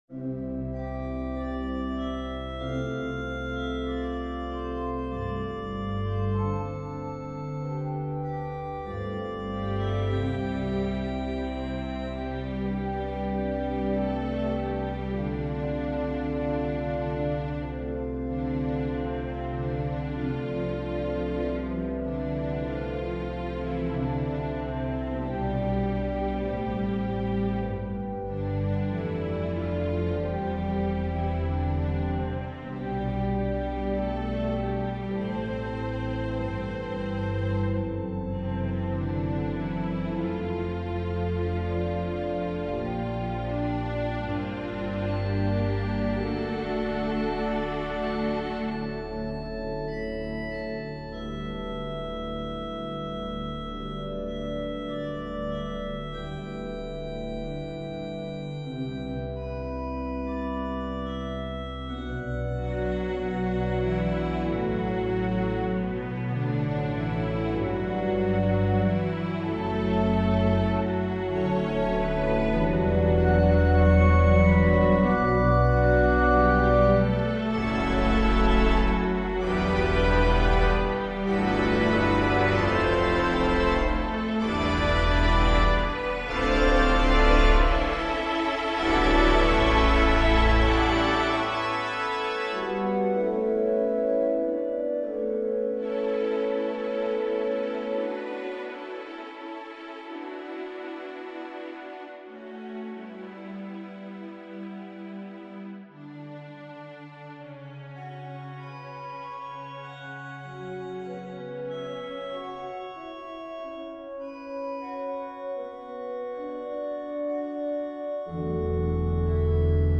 Anthem for SATB choir and organ.